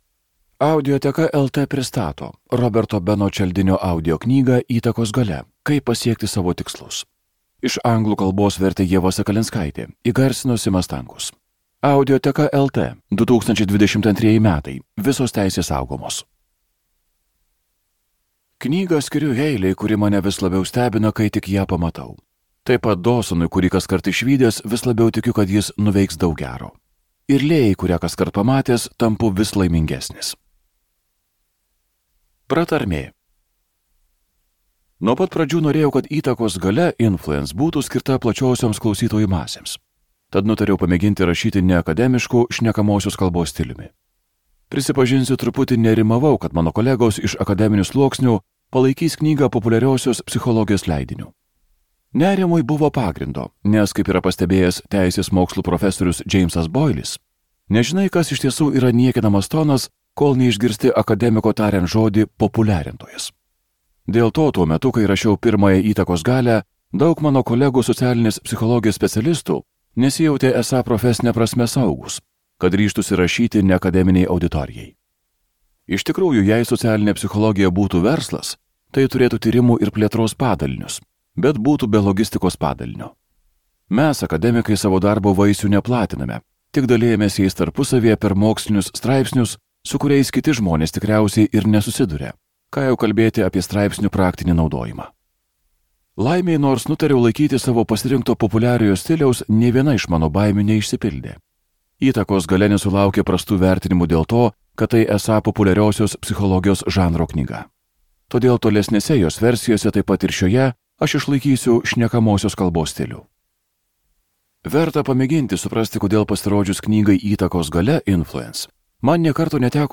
Skaityti ištrauką play 00:00 Share on Facebook Share on Twitter Share on Pinterest Audio Įtakos galia.